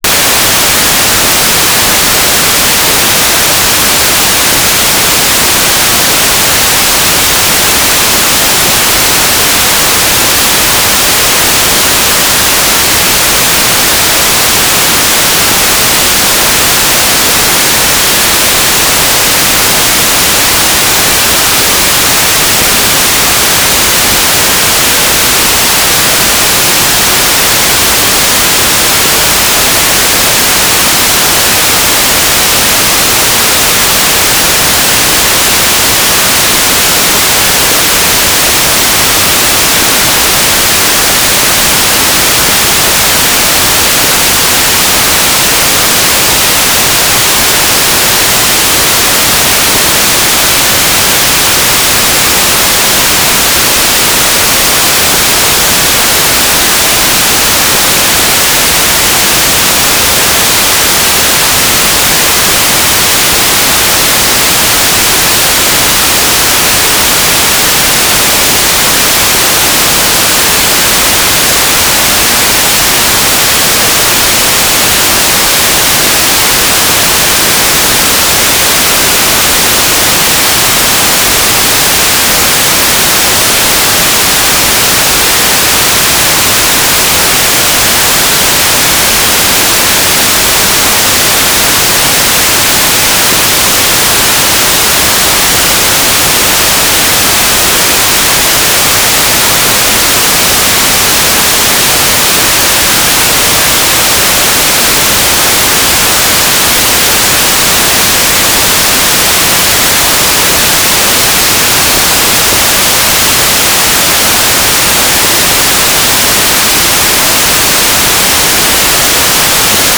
"transmitter_description": "Mode U - Transmitter",
"transmitter_mode": "FM",